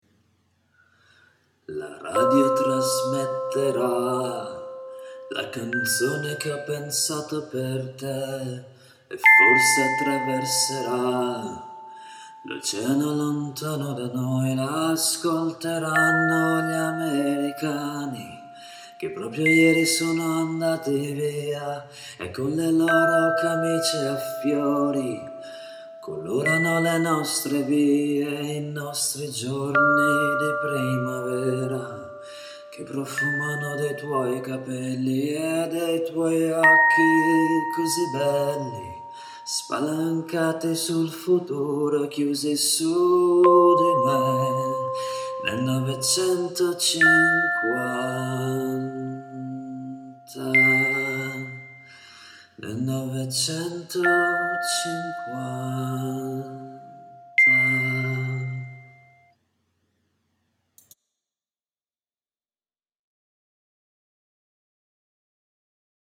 E allora ti canto un pezzetto di canzone. Un minuto solo.